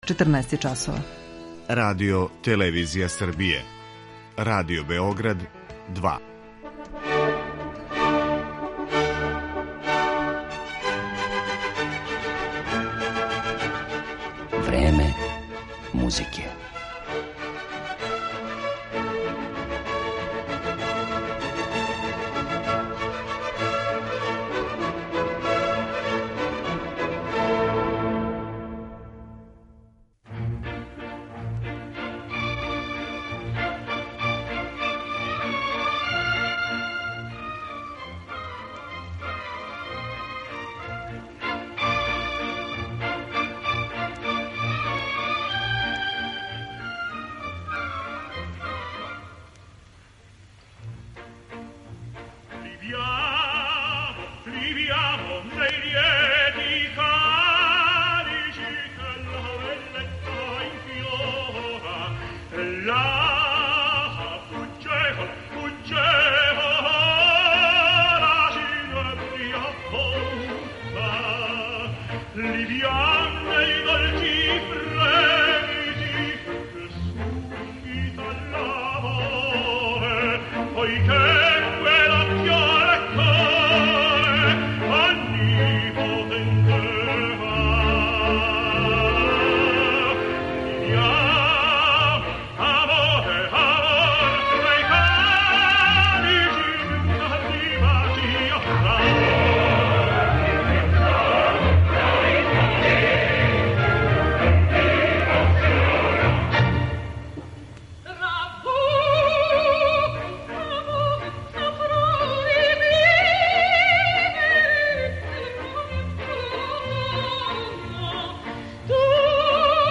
Представићемо га пре свега у оперском репертоару са којим се прославио, али и у концертном у коме је руководио неким од набољих оркестара на свету. Између осталог, моћи ћете да чујете и одломак легендарног архивског снимка продукције опере „Травијата" Ђузепа Вердија коју је режирао Лукино Висконти и у којој су солисти били Марија Калас и Ђузепе ди Стефано.